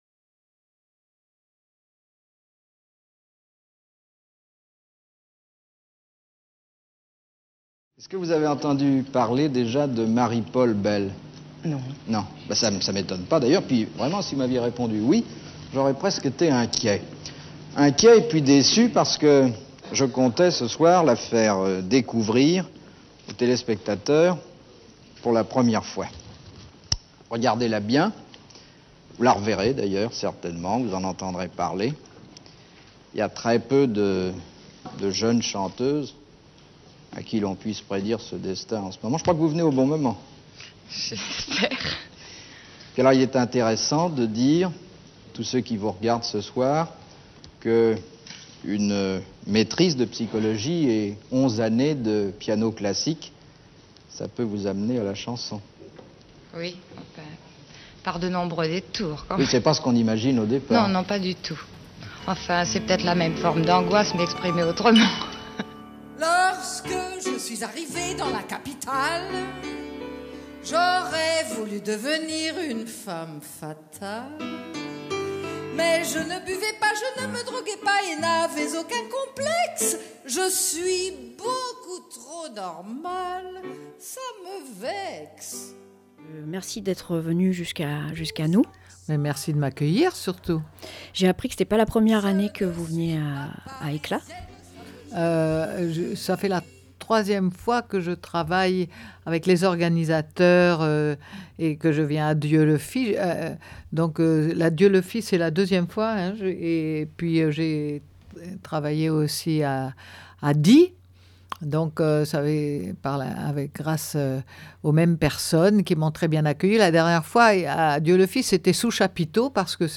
Nous avons eu la chance de recevoir la très grande Marie-Paule Belle au studio de RadioLà, elle nous parle de son dernier spectacle qu’elle a joué au Festival Eclats au Pays de Dieulefit – festival de la voix….
Marie-Paule Belle 24 août 2017 14:58 | Interview Marie-Paule Belle débute en 1970 au cabaret l’Ecluse, comme Barbara dix ans auparavant, s’accompagnant à la guitare plutôt qu’au piano.
ITW-M.P-Belle.mp3